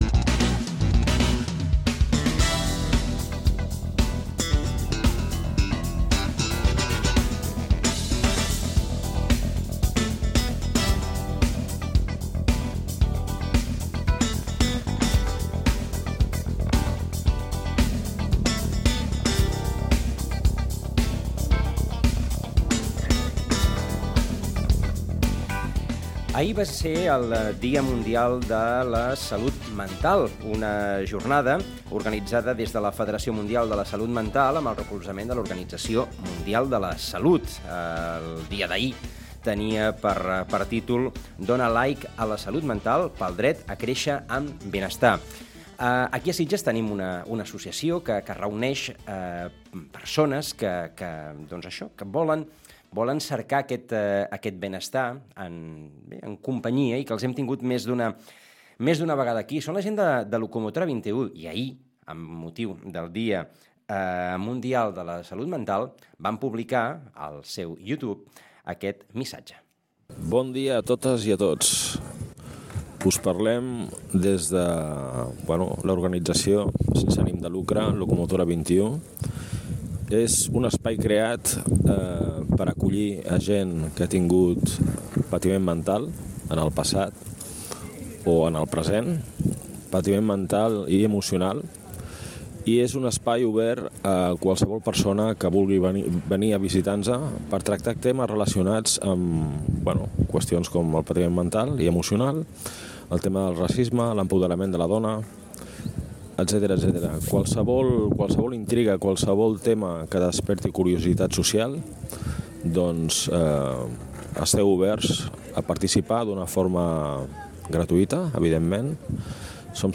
Locomotora XXI, el grup de trobada per a persones amb patiment mental, va commemorar el dia mundial de la salut mental amb un missatge positiu, i amb una tèrtúlia a Ràdio Maricel